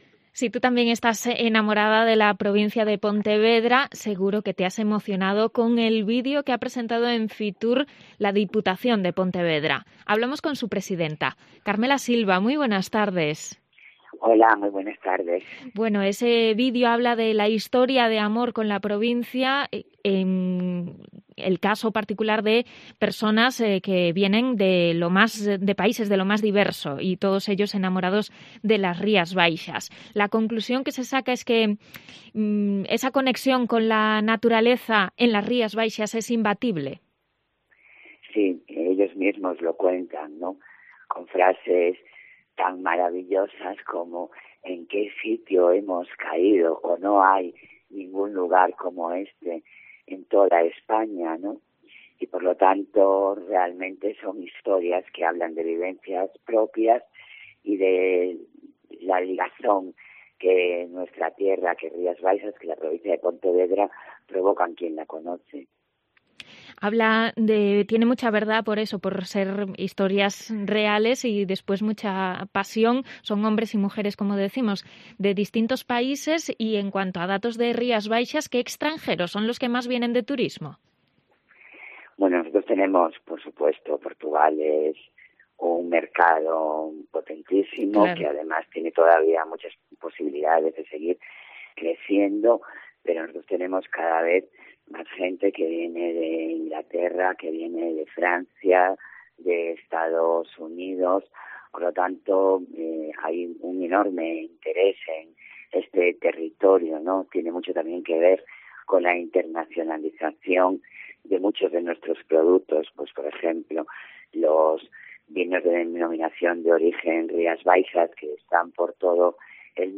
Entrevista con la presidenta de la Diputación de Pontevedra, Carmela Silva, sobre FITUR
Madrid - Publicado el 21 ene 2022, 14:00 - Actualizado 18 mar 2023, 07:53